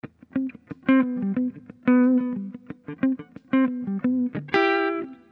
Loops guitares rythmique- 100bpm 3
Guitare rythmique 58